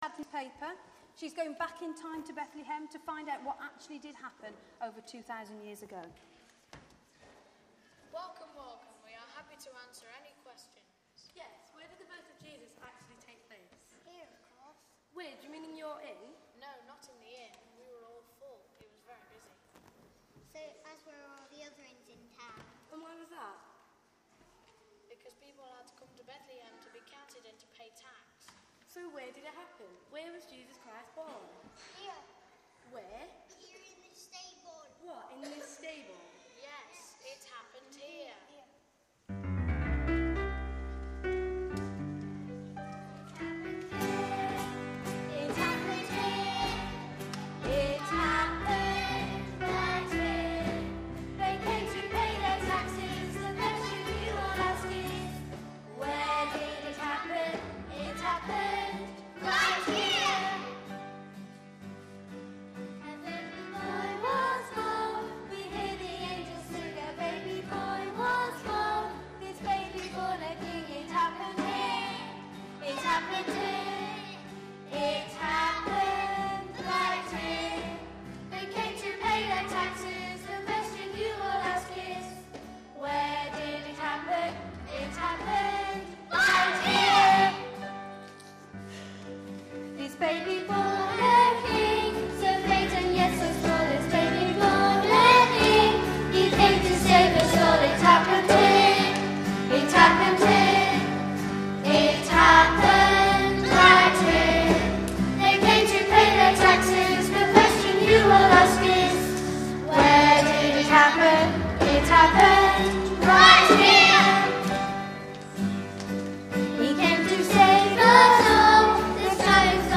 Highlights of the All Age Nativity Service
Talk by in 10:30 Morning Worship, St John's service